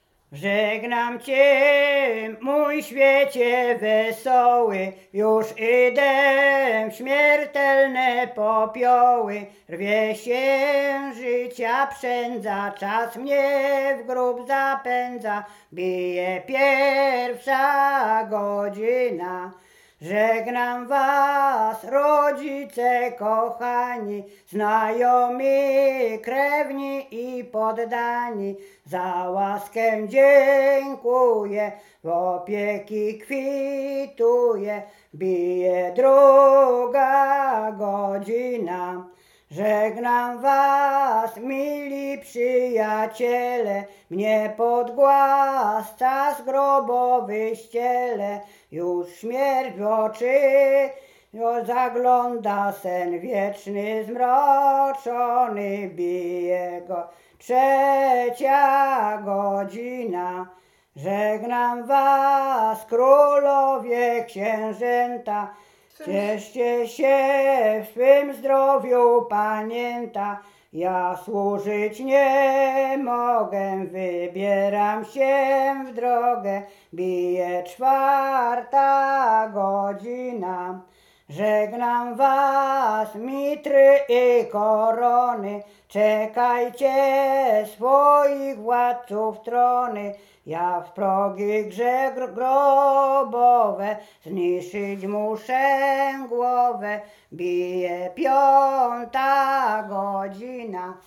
Łęczyckie
województwo łódzkie, powiat poddębicki, gmina Wartkowice, wieś Sucha Dolna
Pogrzebowa
pogrzebowe nabożne katolickie do grobu